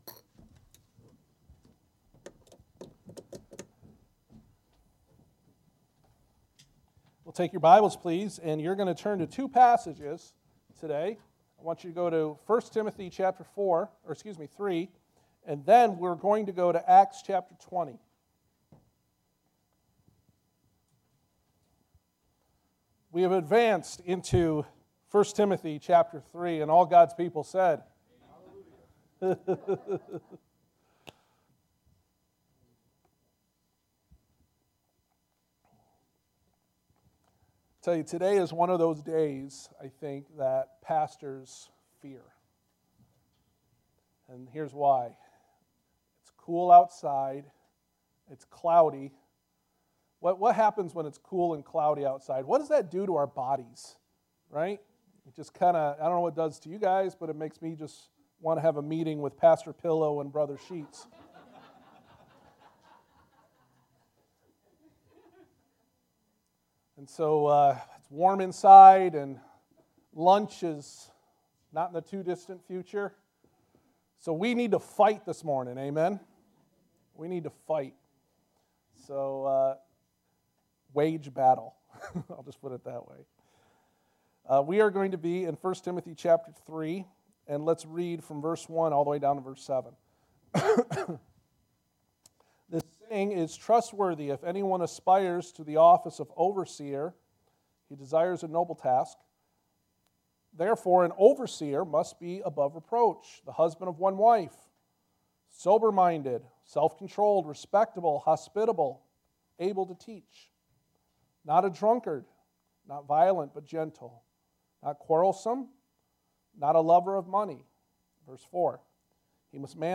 Passage: 1 Timothy 3:1-13 Service Type: Sunday Morning